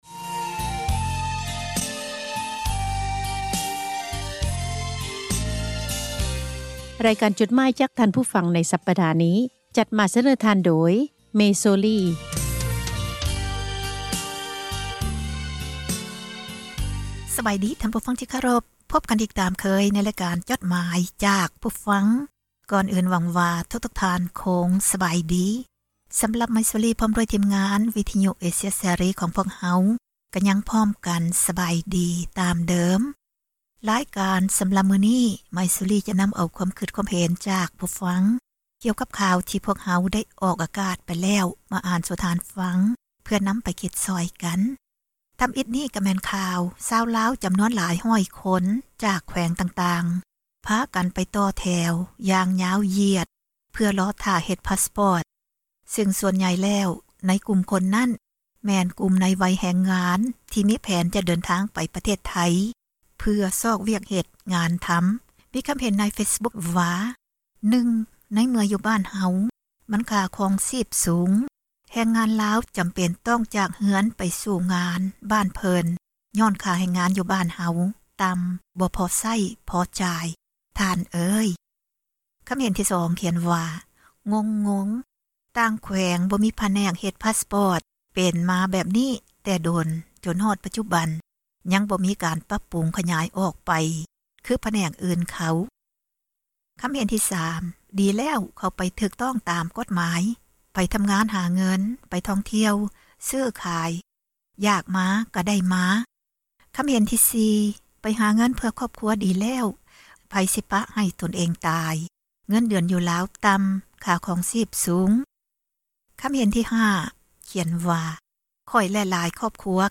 ອ່ານຈົດໝາຍ, ຄວາມຄຶດຄວາມເຫັນ ຂອງທ່ານ ສູ່ກັນຟັງ ເພື່ອເຜີຍແຜ່ ທັສນະ, ແນວຄິດ ທີ່ສ້າງສັນ, ແບ່ງປັນ ຄວາມຮູ້ ສູ່ກັນຟັງ.